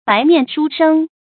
白面书生 bái miàn shū shēng
白面书生发音
成语正音生，不能读作“sēnɡ”。